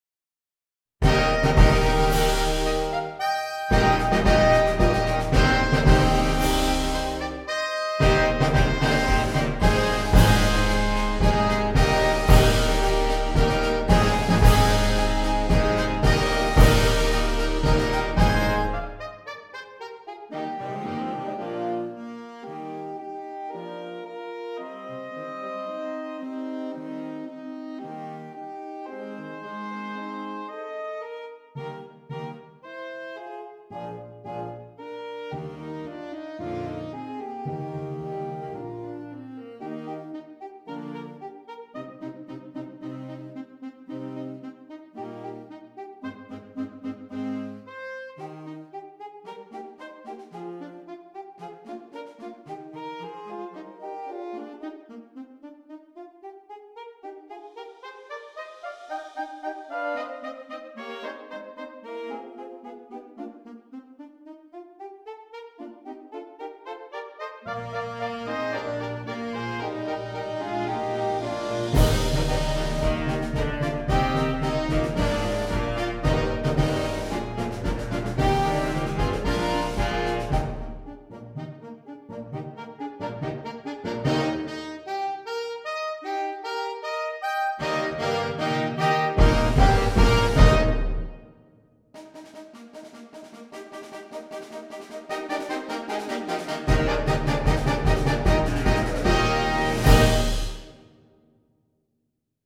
Saxophone Ensemble